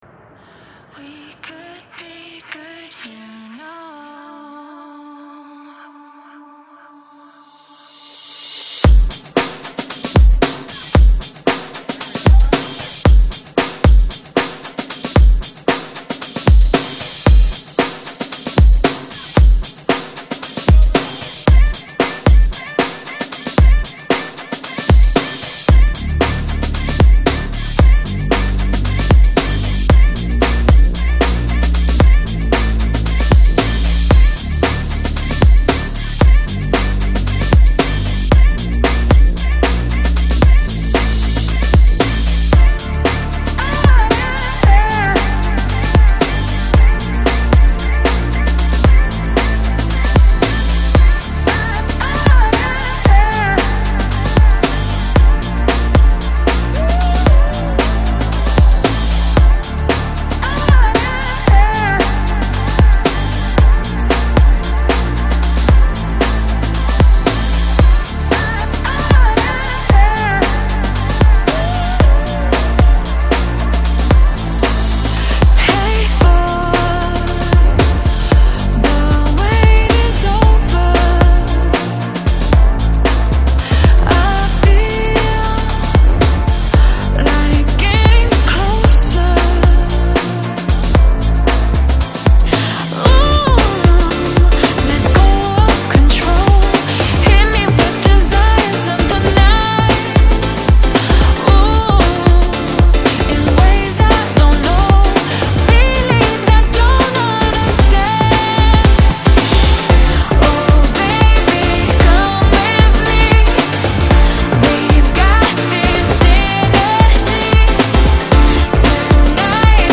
Downtempo | Electronic | Indie Dance